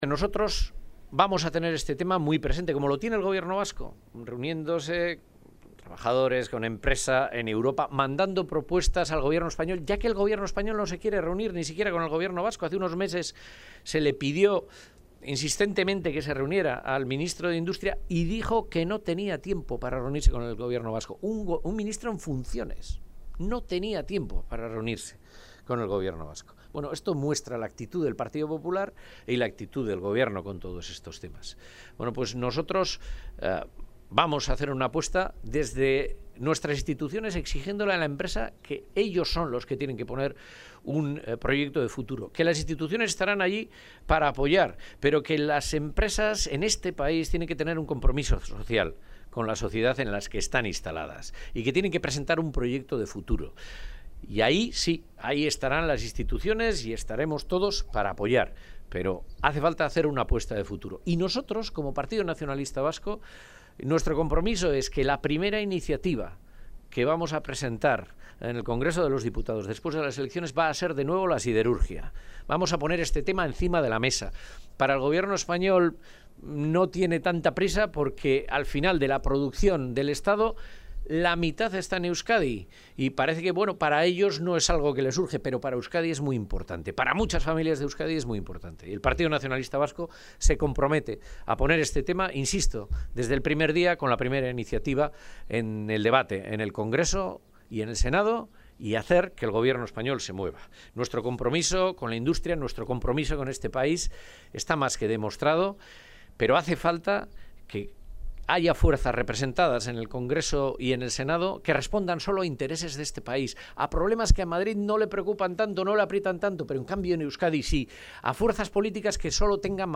Portugalete. Acto sectorial. Industria y Siderurgia Aitor Esteban